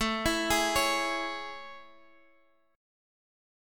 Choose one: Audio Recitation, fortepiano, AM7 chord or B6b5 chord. AM7 chord